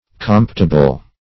Search Result for " comptible" : The Collaborative International Dictionary of English v.0.48: Comptible \Compt"i*ble\, a. [See Compt , v. t.]